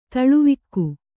♪ taḷuvikku